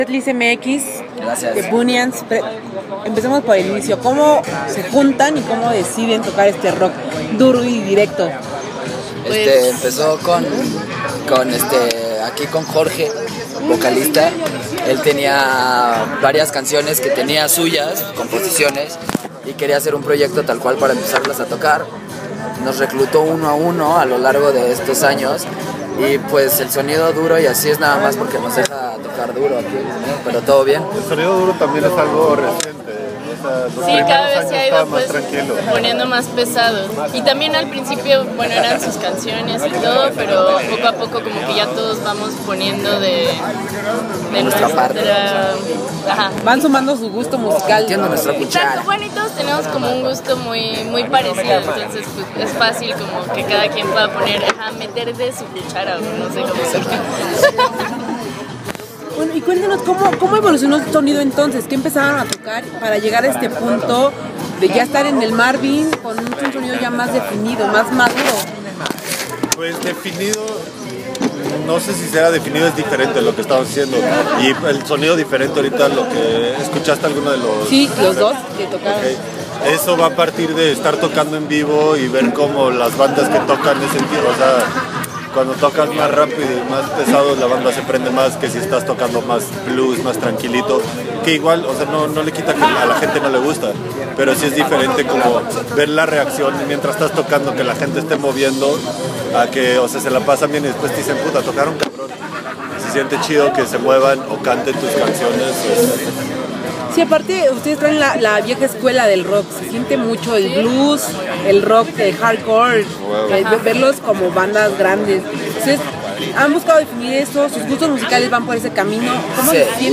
Tuvimos la oportunidad de charlar con ellos antes de su presentación en el Marvin y nos contaron como han sobrellevado este repentino éxito, sus planes a futuro, la invitación al festival y como ha sido esta experiencia.